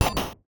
UIClick_Menu Double Mallet Metal Hollow 01.wav